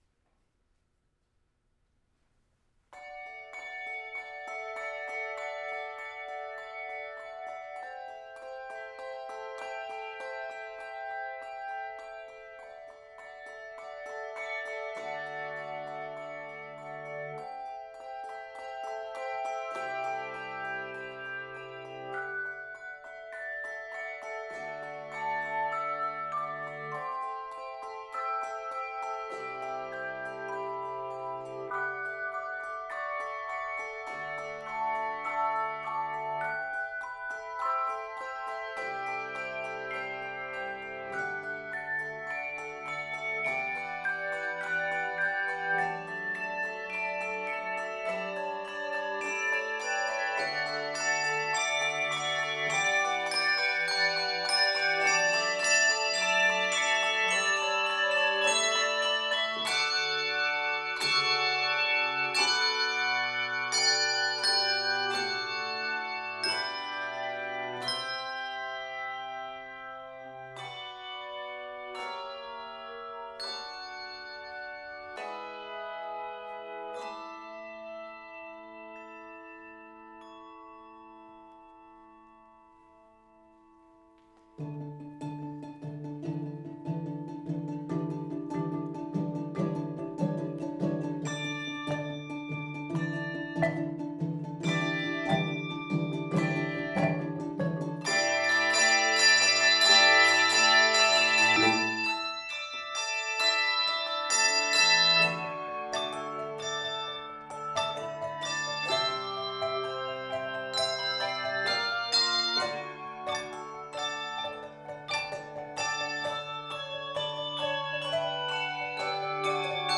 Voicing: Handbells 4-7 Octave